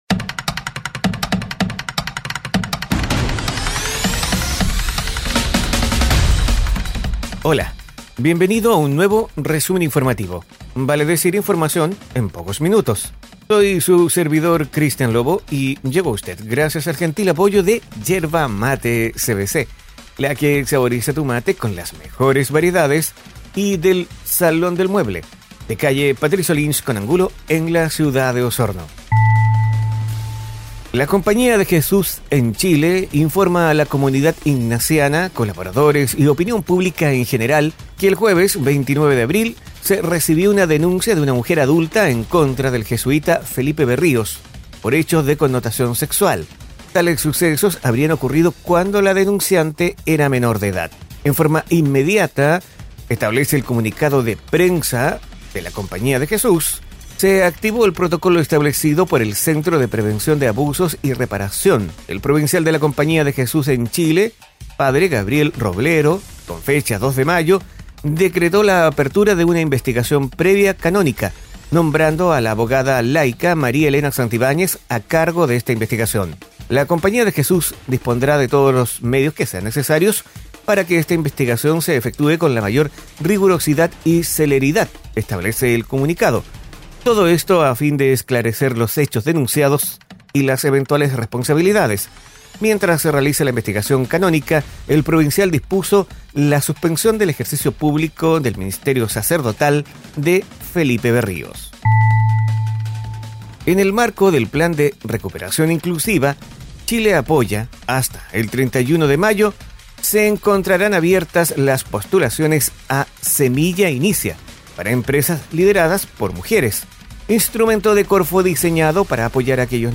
Resumen Informativo ▶ Podcast 04 de mayo de 2022